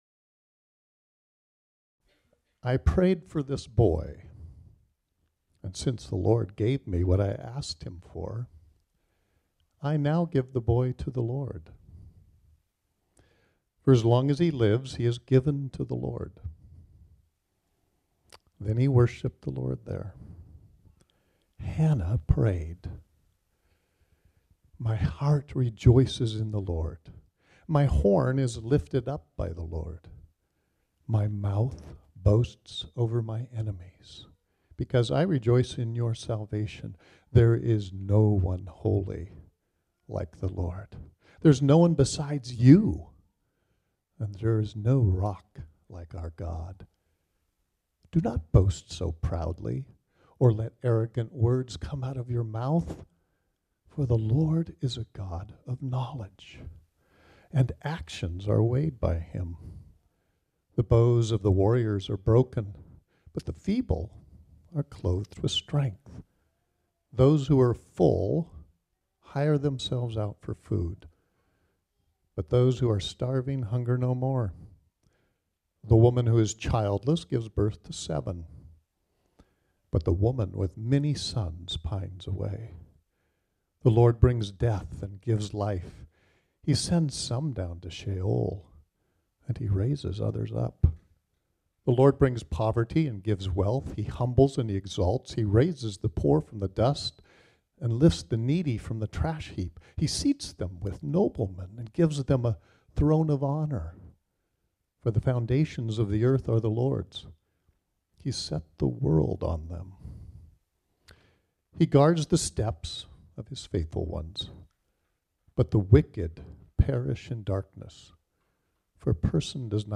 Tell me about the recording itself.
This sermon was originally preached on Sunday, April 16, 2023.